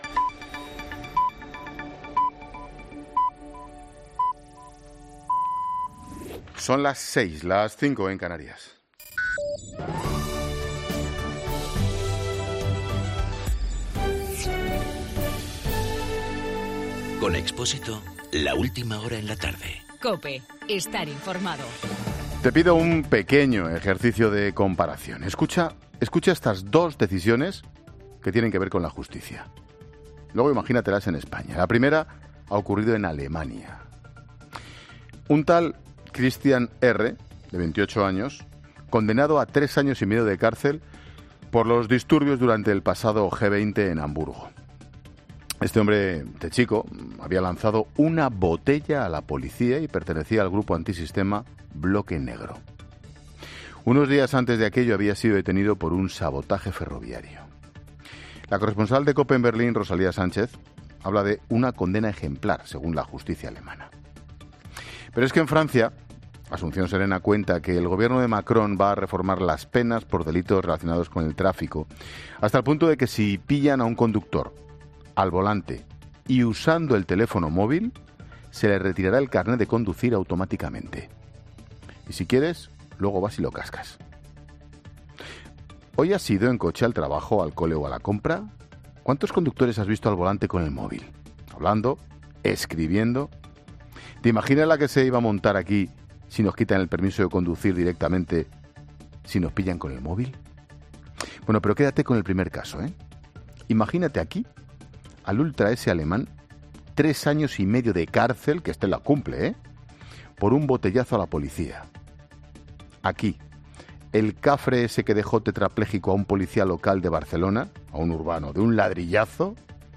Monólogo de Expósito
El comentario de Ángel Expósito siguiendo casos en Alemania y Francia.